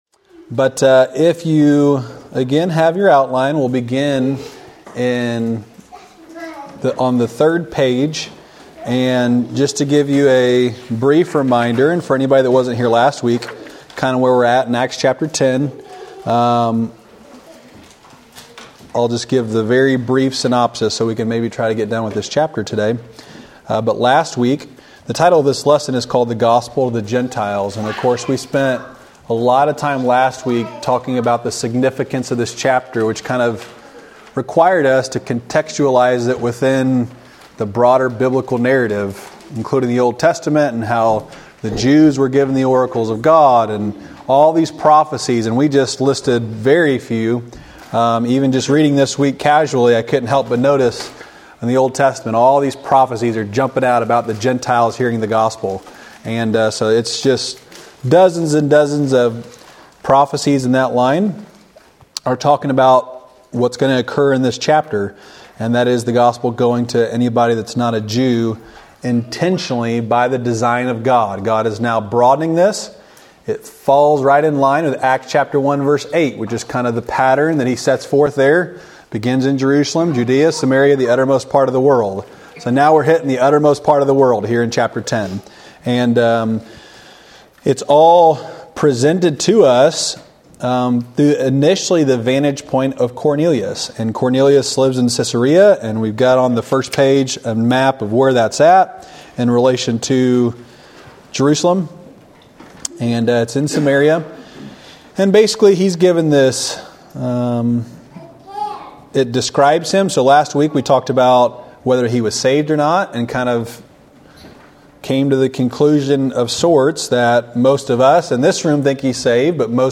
Wednesday night lesson from January 10, 2024 at Old Union Missionary Baptist Church in Bowling Green, Kentucky.